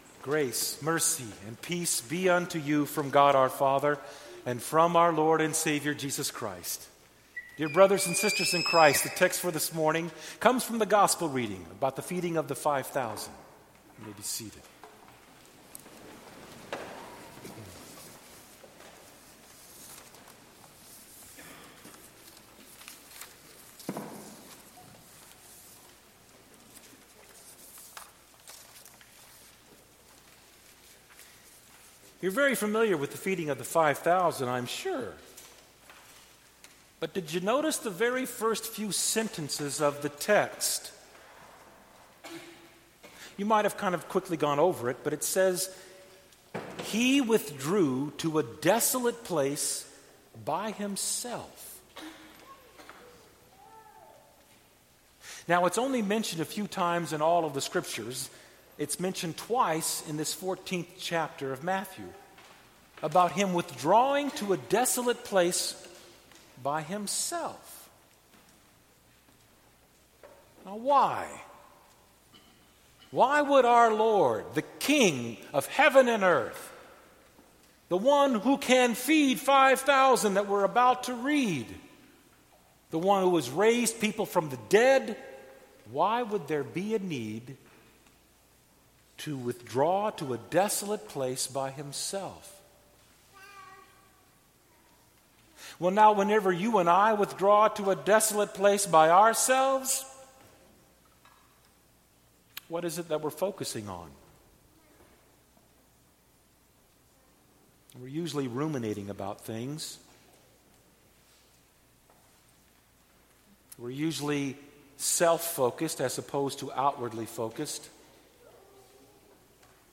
Series 8th Sunday after Pentecost